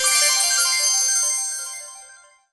buy_gems_01.wav